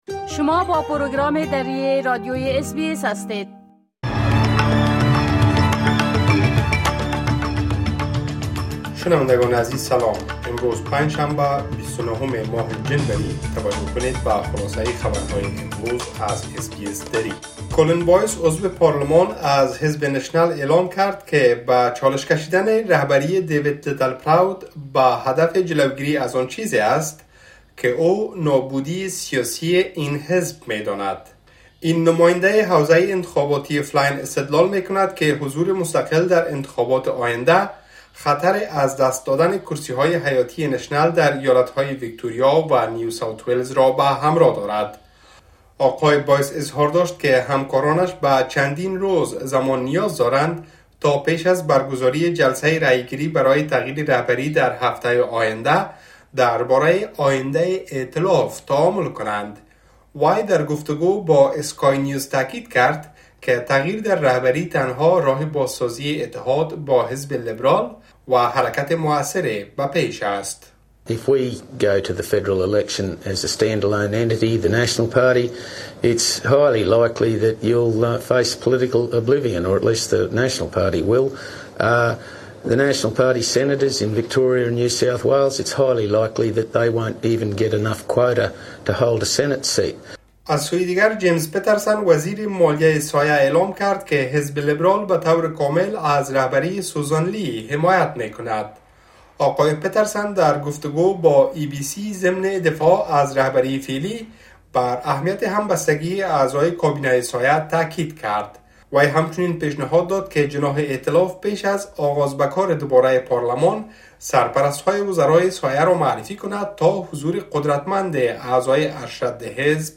خلاصه‌ای مهم‌ترين خبرهای روز | ۲۹ جنوری